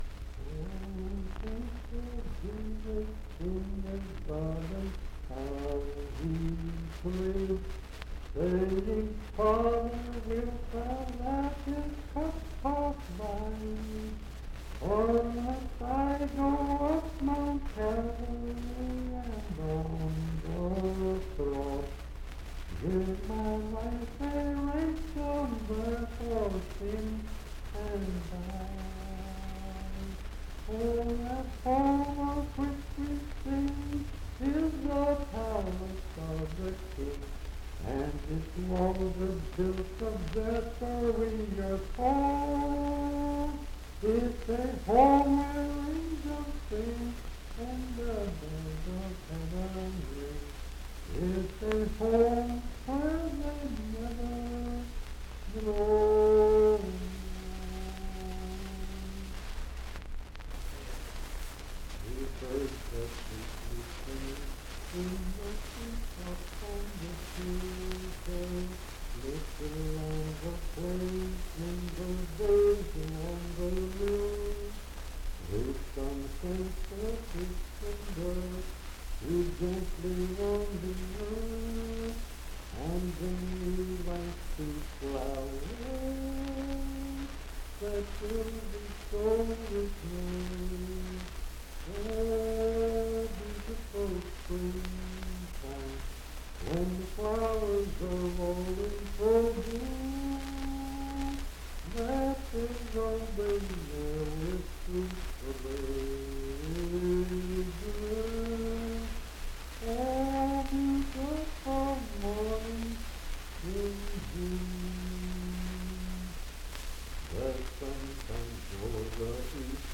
Unaccompanied vocal music
Verse-refrain 3d(4) & R(4).
Miscellaneous--Musical
Voice (sung)